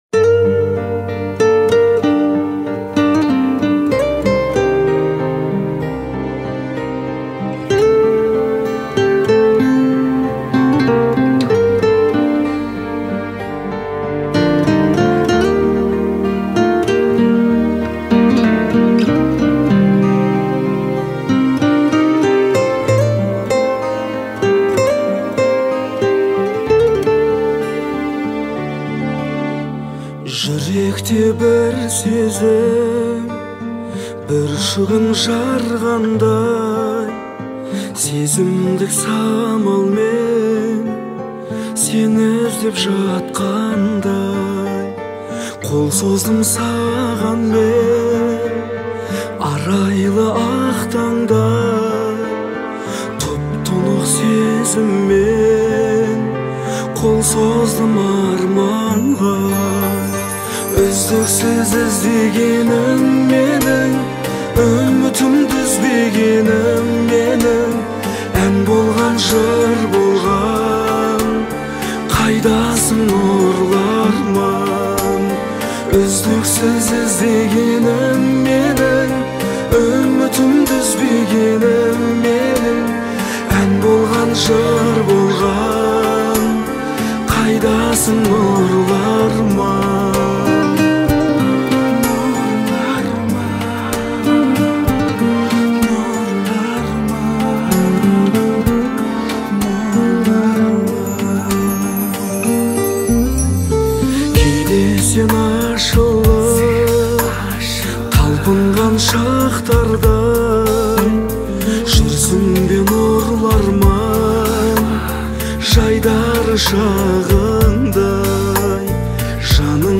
относится к жанру поп и обладает вдохновляющим настроением